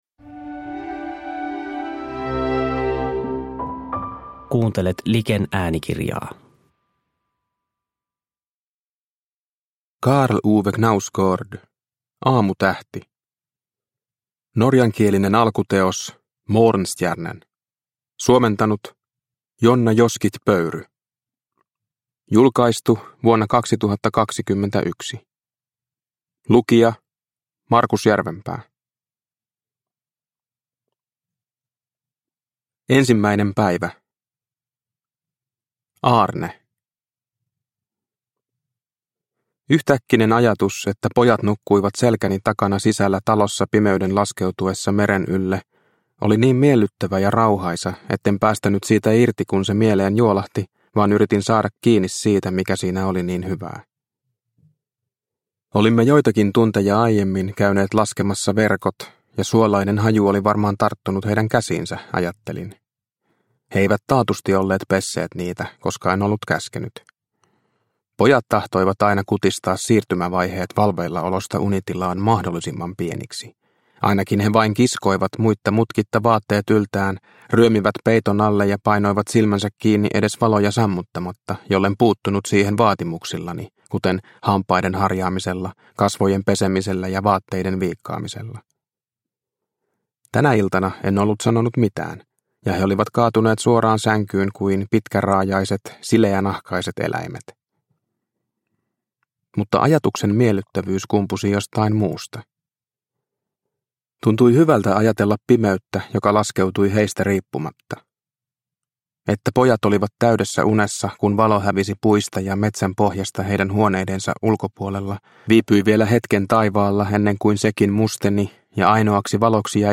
Aamutähti – Ljudbok – Laddas ner